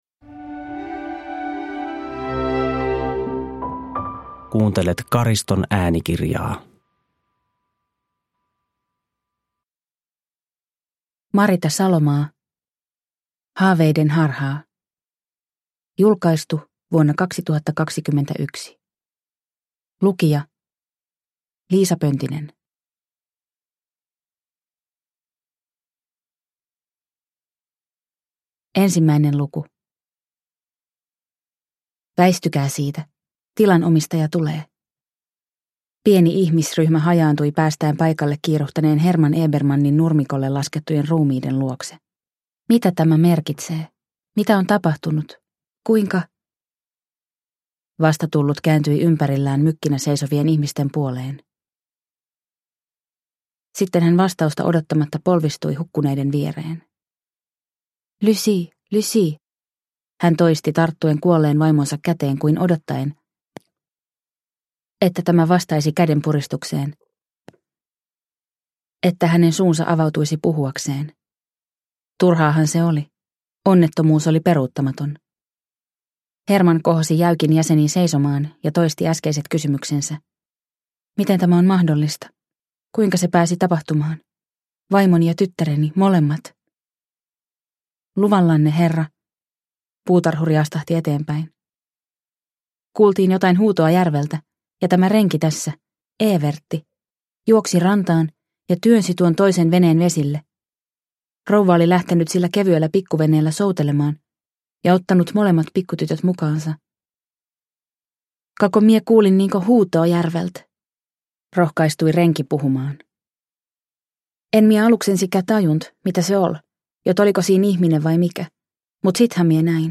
Haaveiden harhaa – Ljudbok – Laddas ner